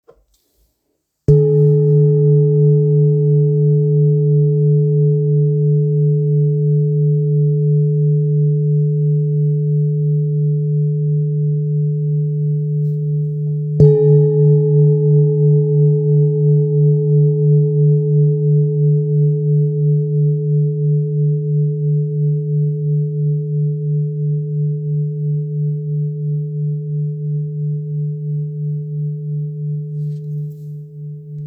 Kopre Singing Bowl, Buddhist Hand Beaten, Antique Finishing, Select Accessories
Material Seven Bronze Metal
It is accessible both in high tone and low tone .
In any case, it is likewise famous for enduring sounds. Kopre Antique Singing Bowls is accessible in seven different chakras tone.